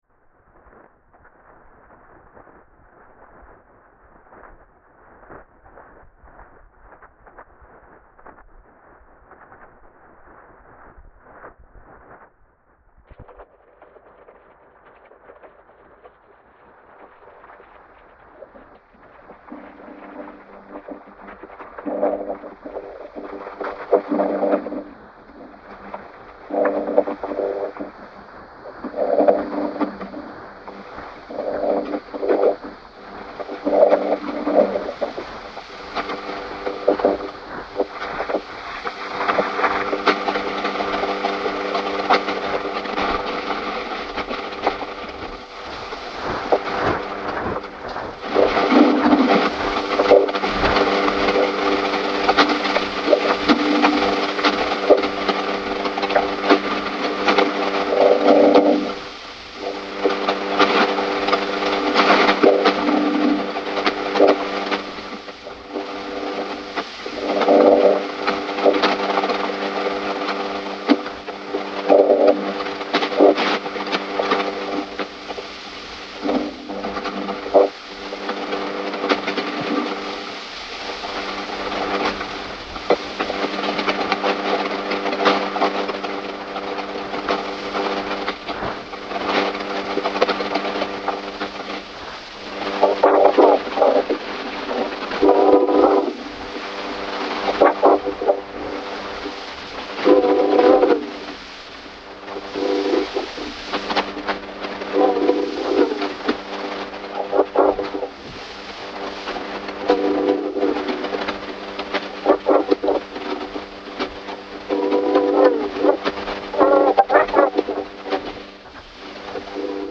Phonophany, or the All-Revealing Medium dramatises the manifestation of occult and hidden mediation processes present in both audio technologies and Zande magic practices. As a composition, it works from a field recording of a Zande revelation song used to detect witches, employing audio mediation to allow the listener to experience a progressive unfolding of the recorded voice from initially unintelligible, noisy signals.
Rather than treating sound recording as transparent representation, the composition foregrounds mediation itself — specifically in the form of electromagnetic noise — as a way of listening oriented toward gradual aural revelation.